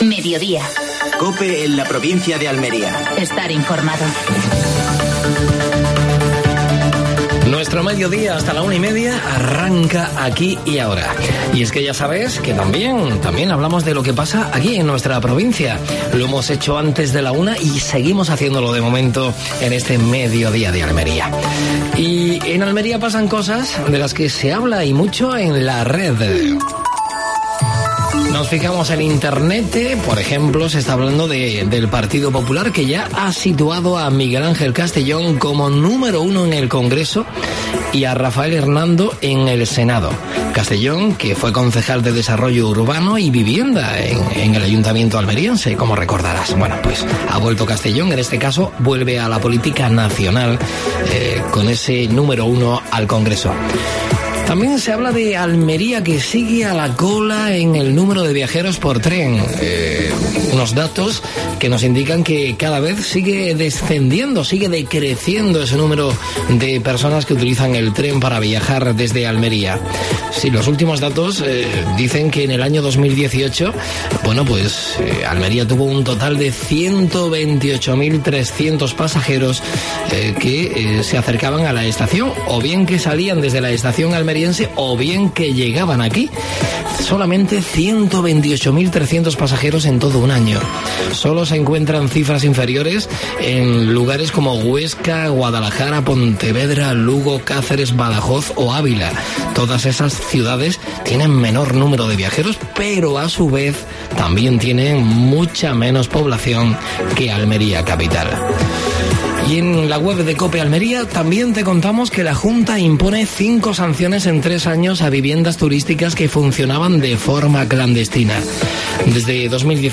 AUDIO: Actualidad en Almería. Entrevista a Fernando Giménez (diputado de Presidencia de la Diputación de Almería).